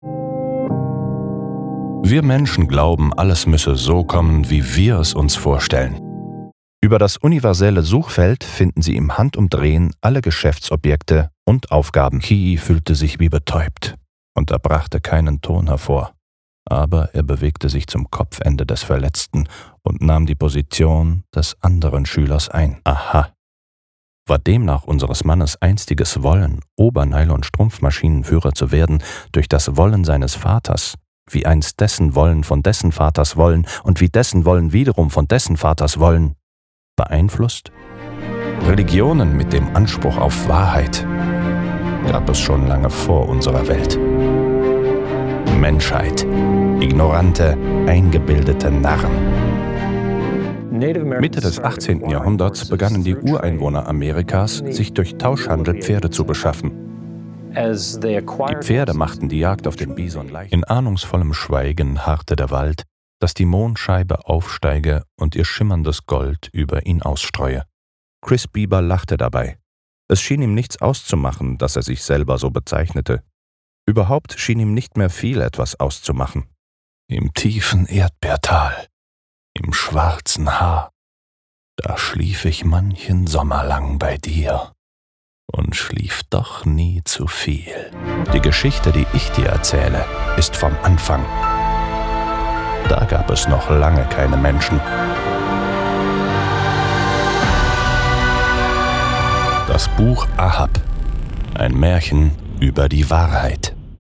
Sprecher-Demo-Trailer